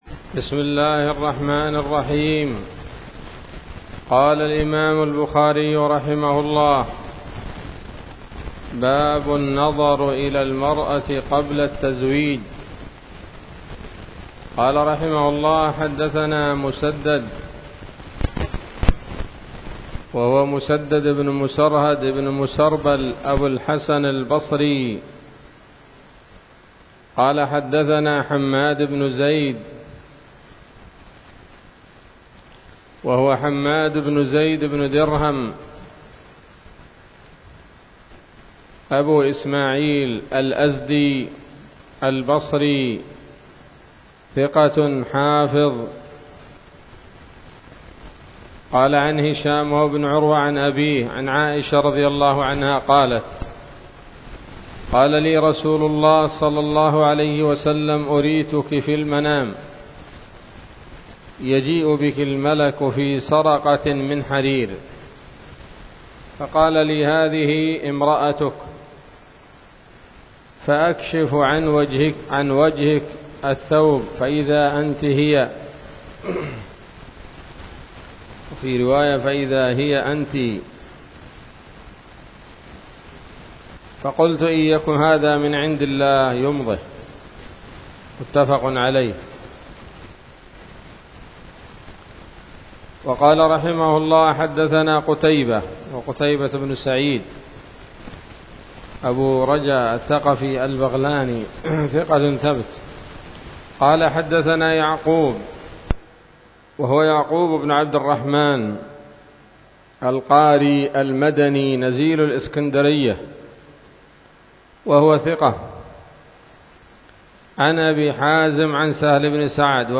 الدرس الرابع والثلاثون من كتاب النكاح من صحيح الإمام البخاري